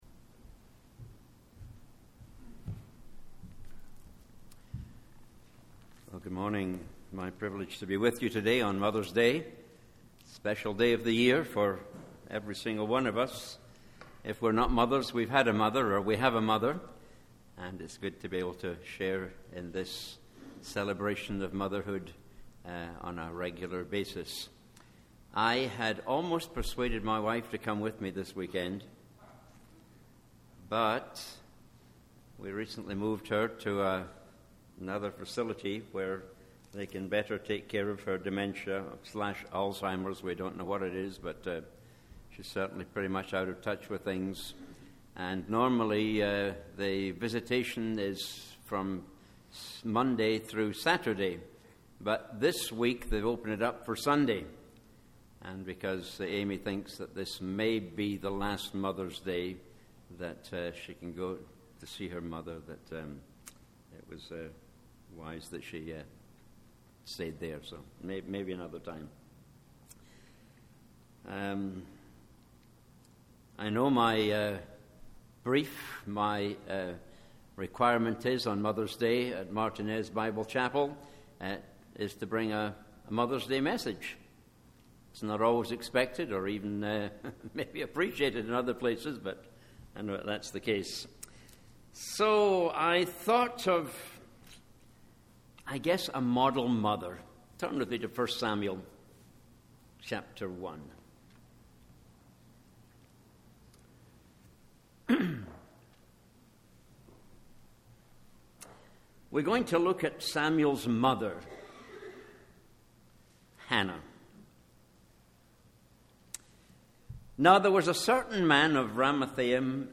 Sermons - Martinez Bible Chapel - Page 7